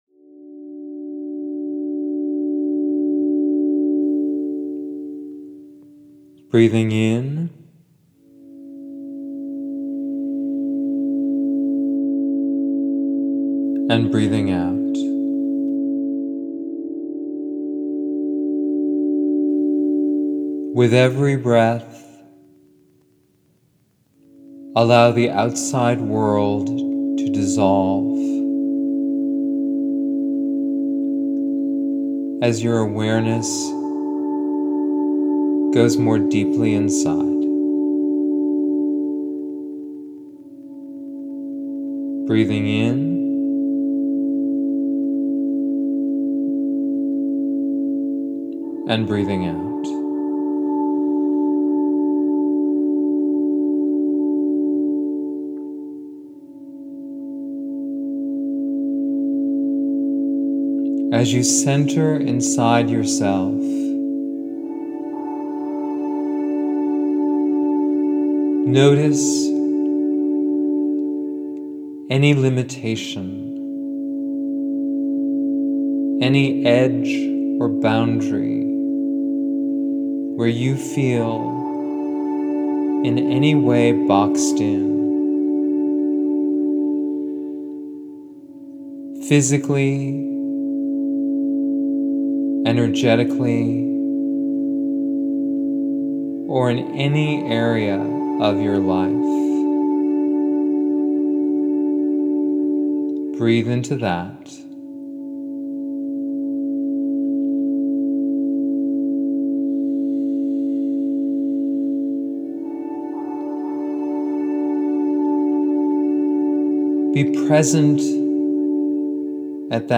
This meditation is 22 minutes long.
Out-of-The-Box-Meditation.mp3